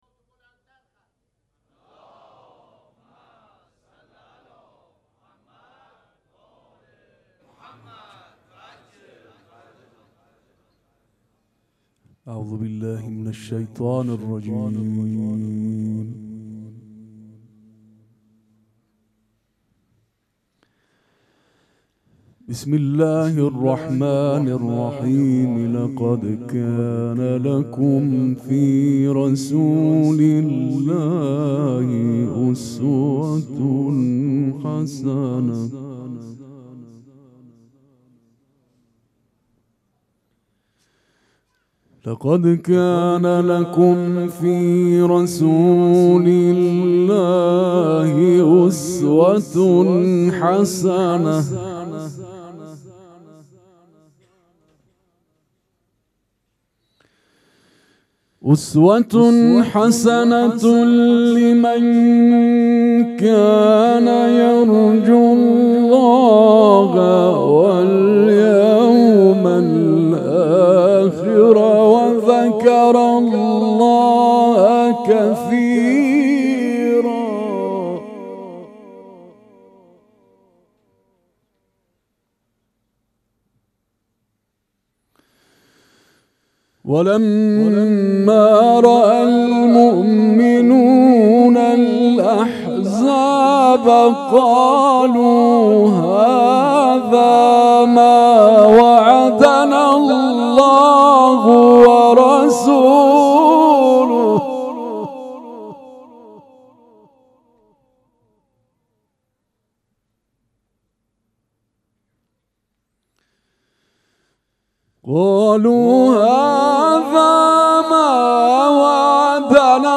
قرآن کریم
مراسم عزاداری شب ششم محرم الحرام ۱۴۴۷
قرائت قرآن